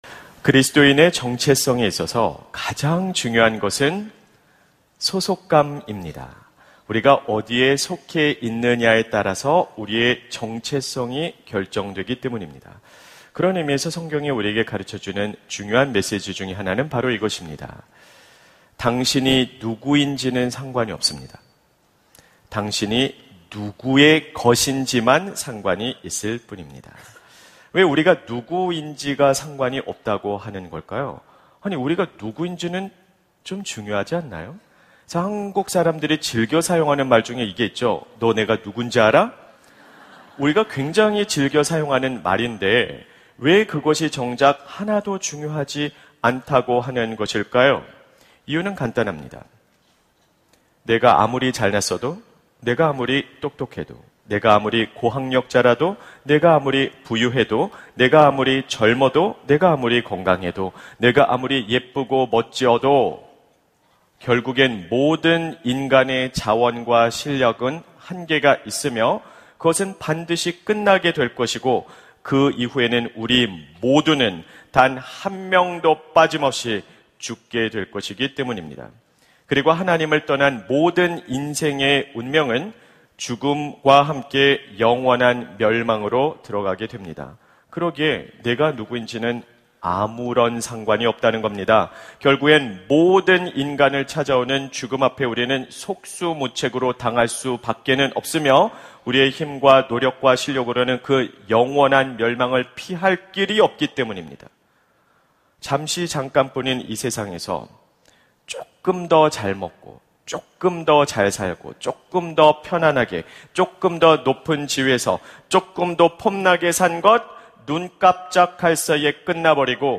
설교 : 수요향수예배 하나님의 숨결이 스며드는 일상 - 당신은 어디 소속입니까?